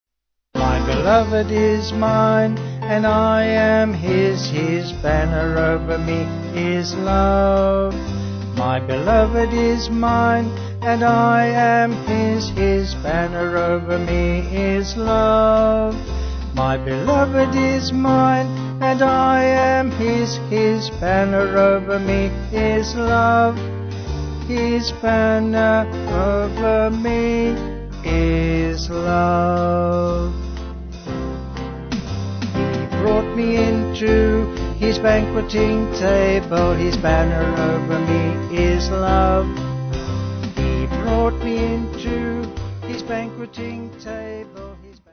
Small Band
Vocals and Band